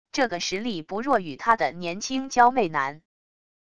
这个实力不弱与他的年轻娇媚男wav音频